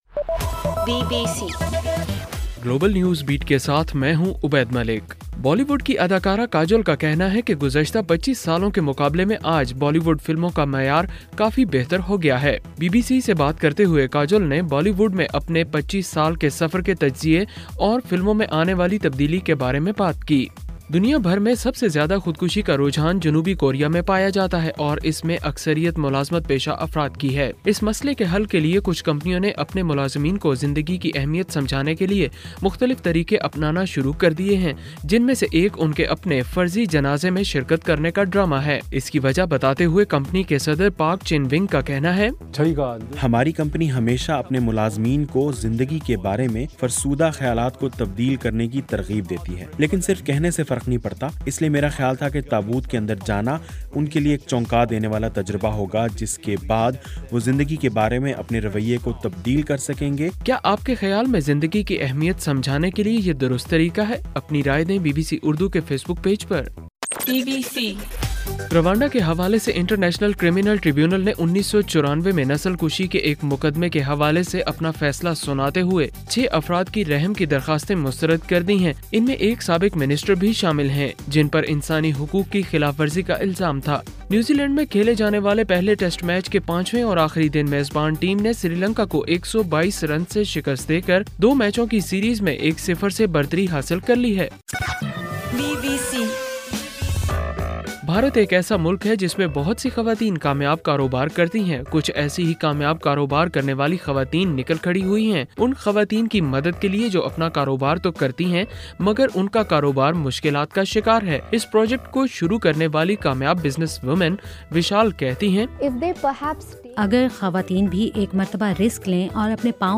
دسمبر 14: رات 10 بجے کا گلوبل نیوز بیٹ بُلیٹن